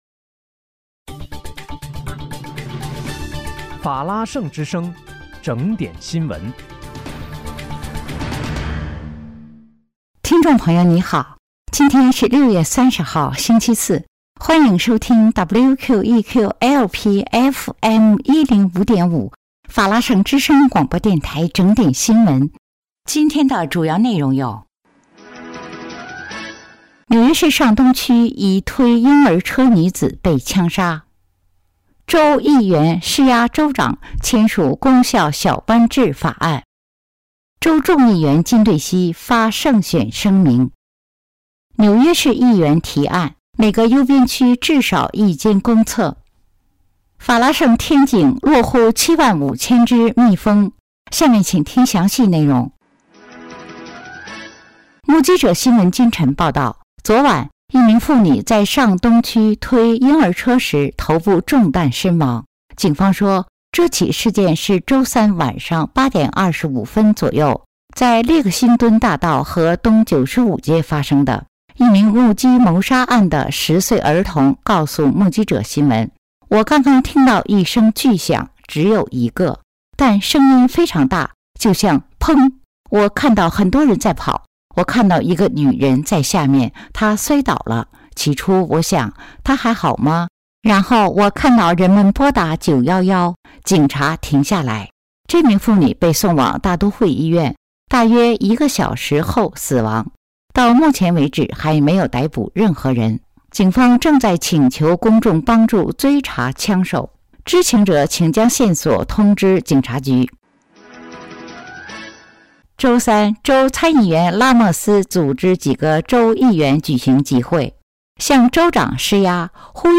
6月30日（星期四）纽约整点新闻